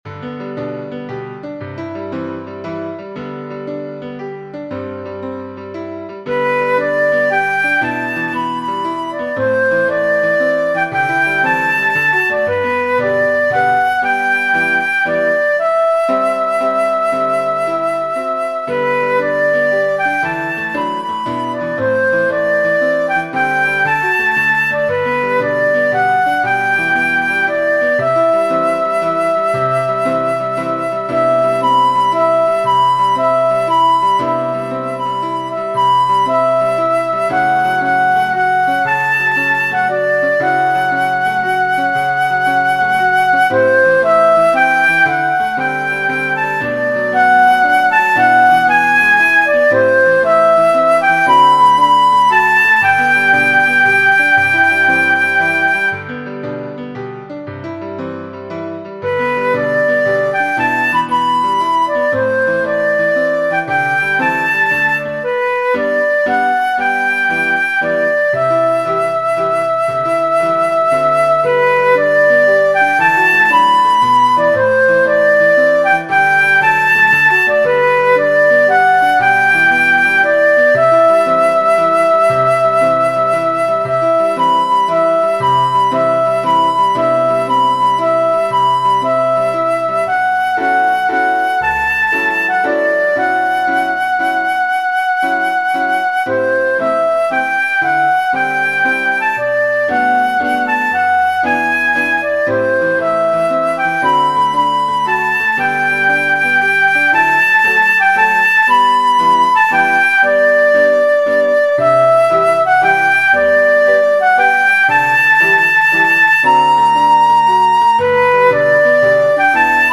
This arrangement is for flute and piano.